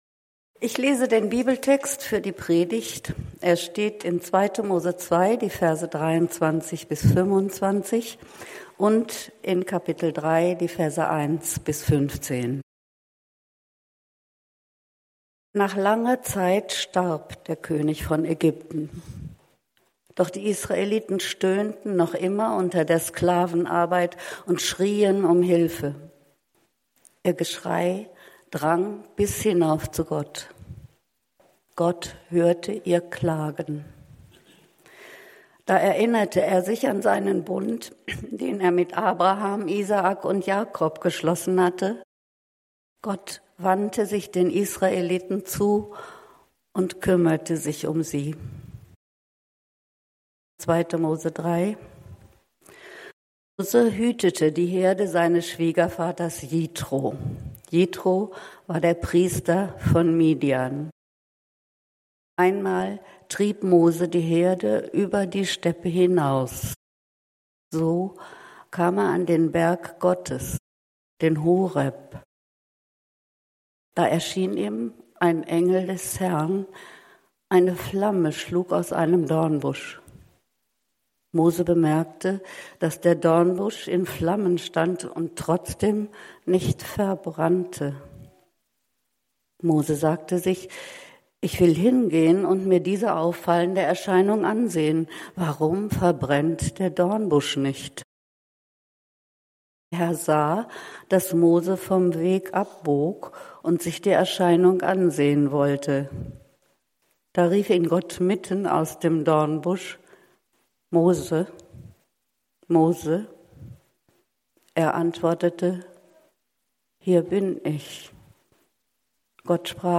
Gottes Name - Geheimnis, das uns ruft ~ Berlinprojekt Predigten Podcast